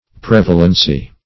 Prevalency \Prev"a*len*cy\, n.
prevalency.mp3